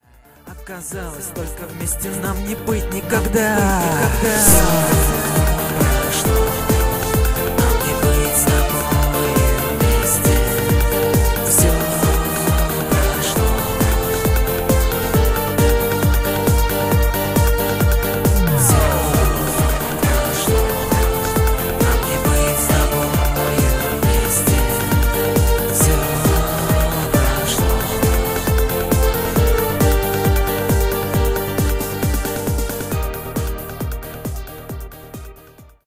• Жанр: Поп